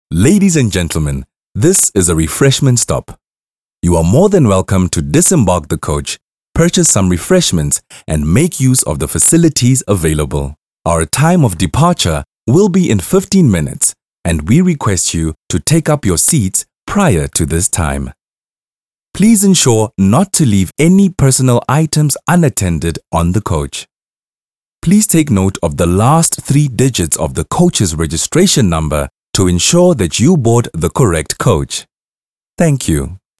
South Africa
authentic, bright, captivating, charming, clear, crisp
My demo reels